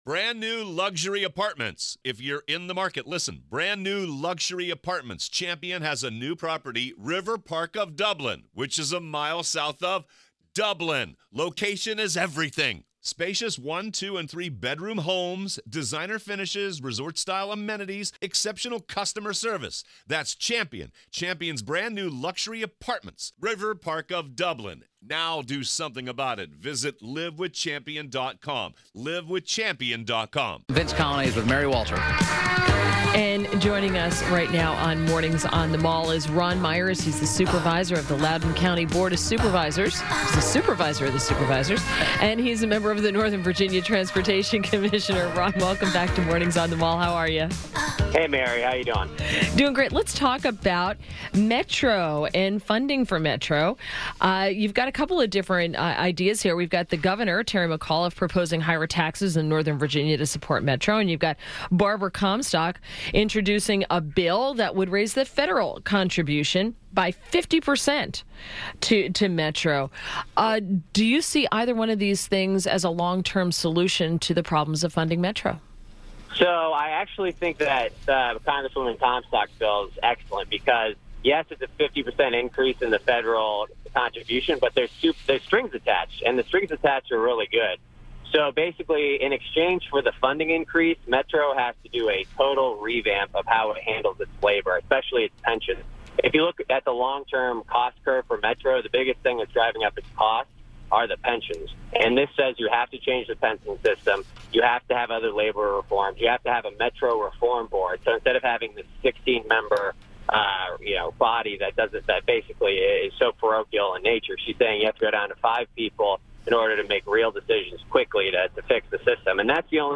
WMAL Interview - RON MEYER - 12.19.17
INTERVIEW - RON MEYER - Supervisor, Loudoun County Board of Supervisors and member of the Northern Virginia Transportation Commission (NVTC). • Loudoun officials welcome Comstock’s Metro reform bill • Gov. Terry McAuliffe proposes higher taxes in Northern Virginia to support Metro Northern Virginians would pay higher taxes on real estate sales, hotel stays and wholesale gasoline to provide Metro with long-sought dedicated funding under a proposal to be announced Monday by Gov. Terry McAuliffe (D).